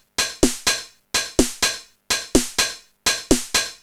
Index of /musicradar/retro-house-samples/Drum Loops
Beat 16 No Kick (125BPM).wav